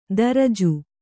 ♪ daraju